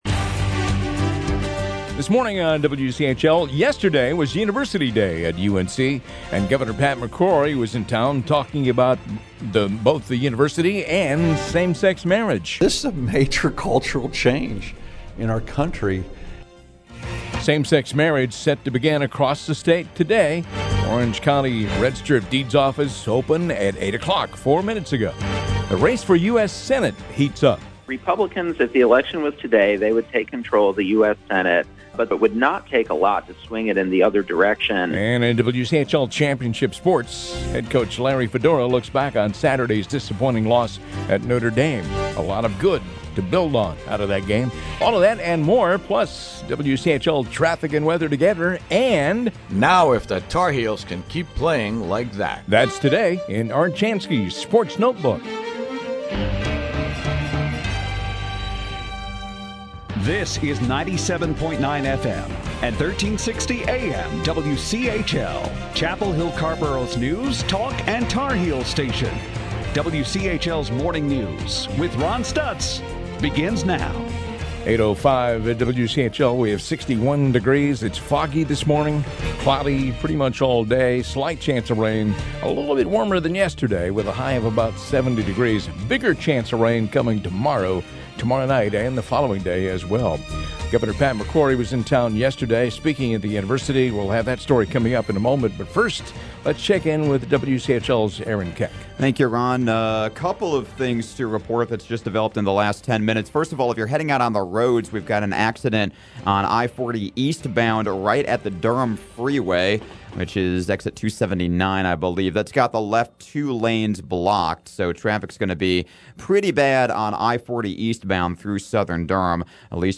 WCHL MORNING NEWS HOUR 3.mp3